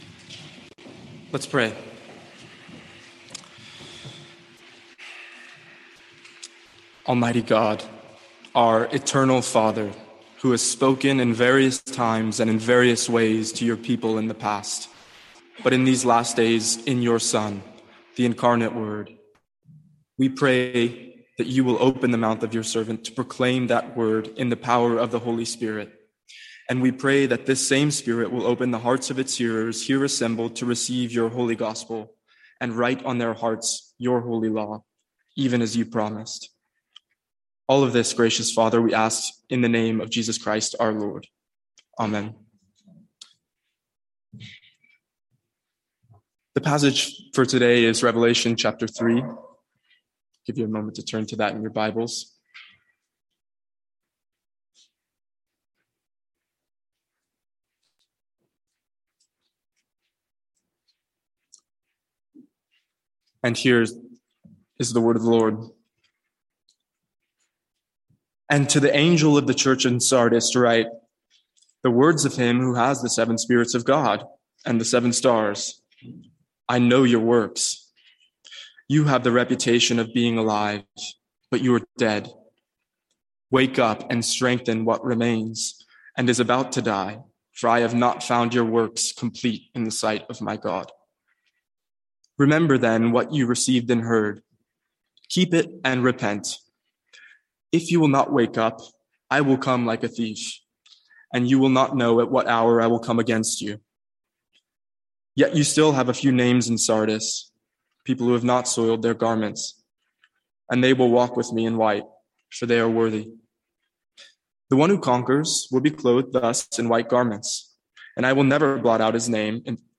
Sermons | St Andrews Free Church
From our evening series in Revelation.